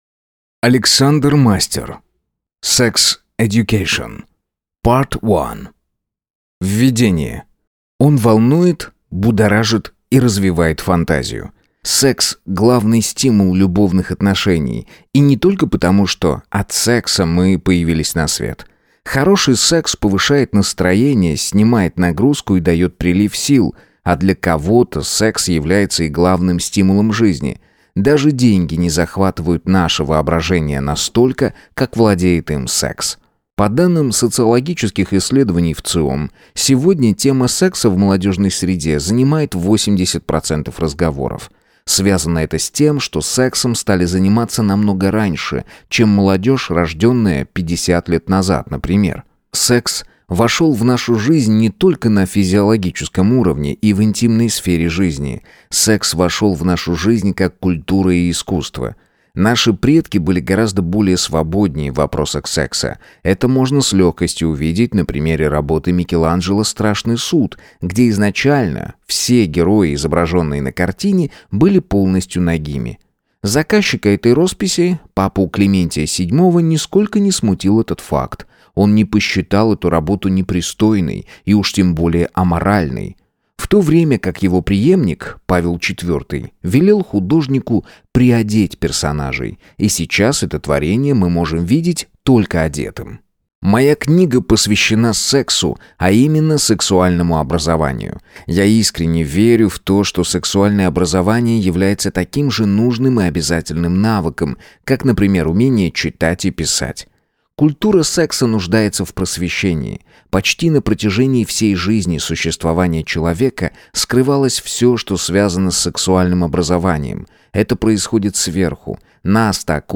Аудиокнига Sex education. Part 1 | Библиотека аудиокниг